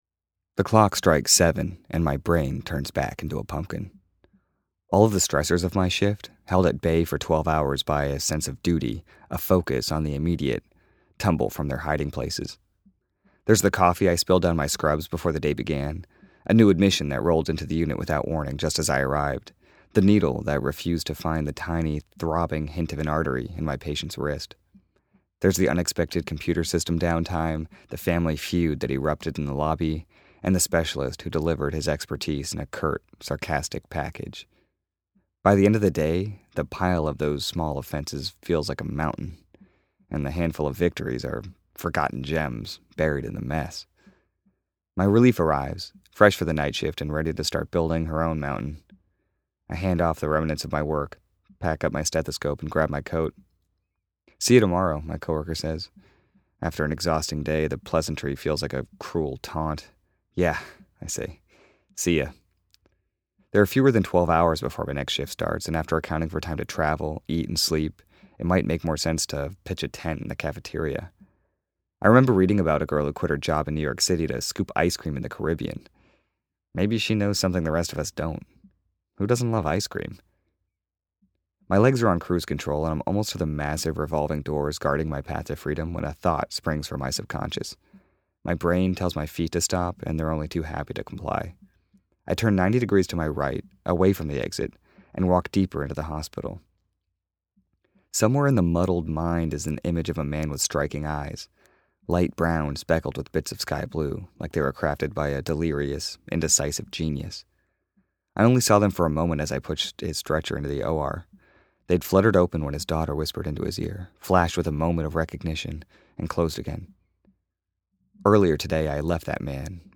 a reading of the essay